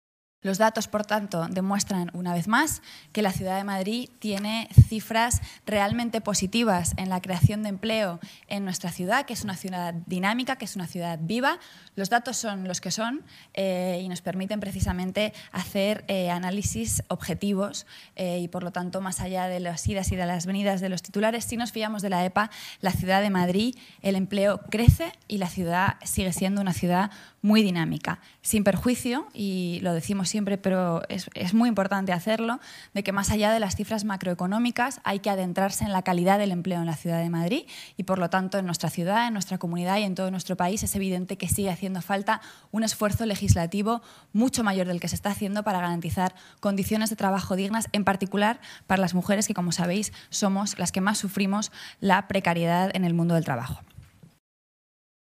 Nueva ventana:La portavoz municipal, Rita Maestre, valora los datos de la EPA